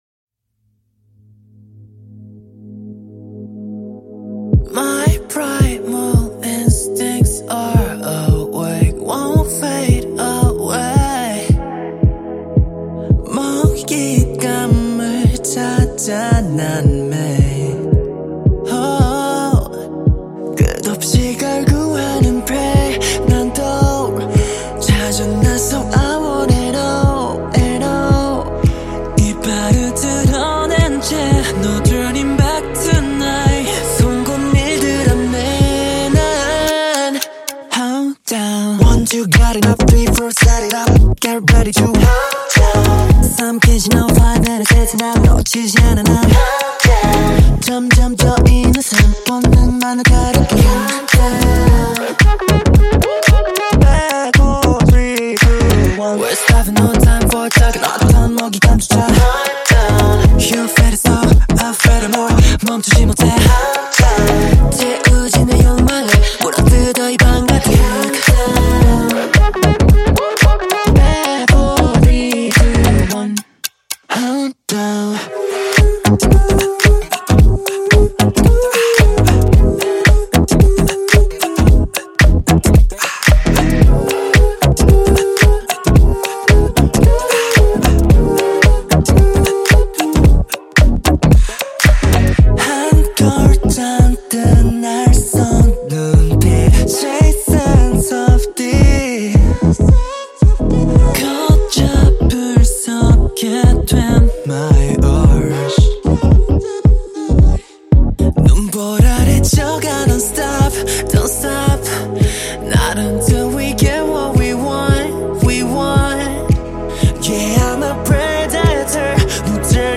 KPop Song